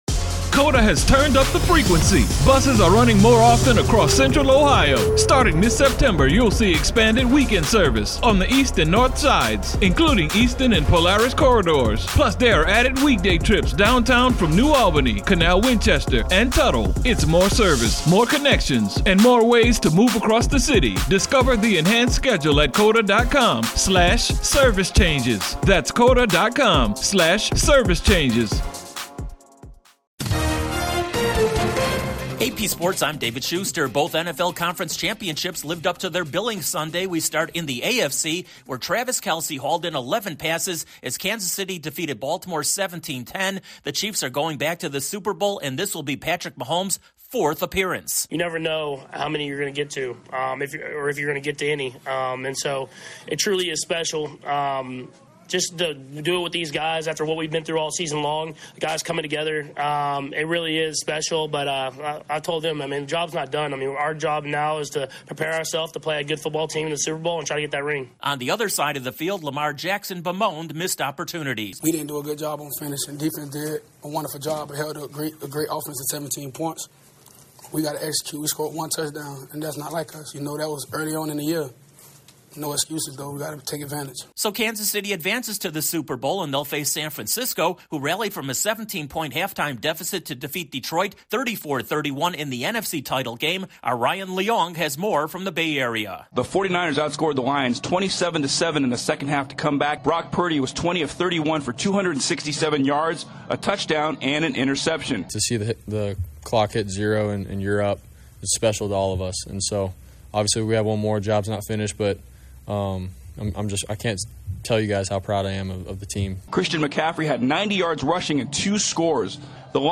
Sports News